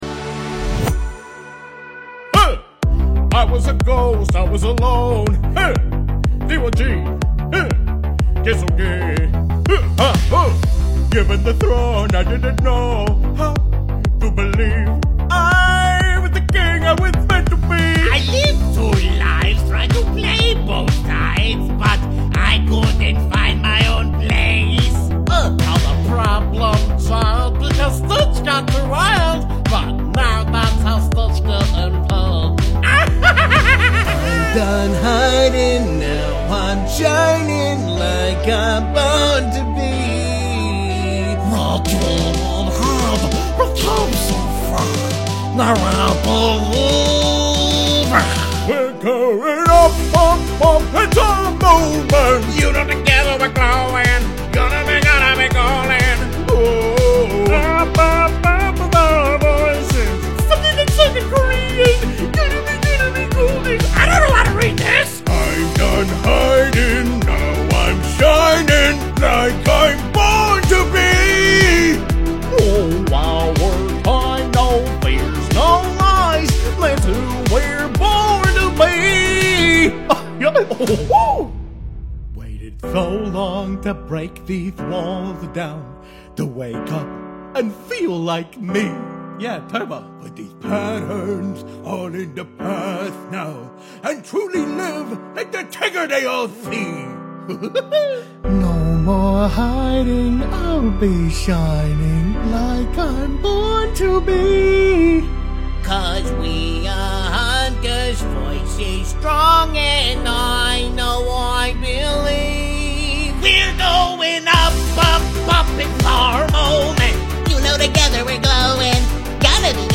Impressions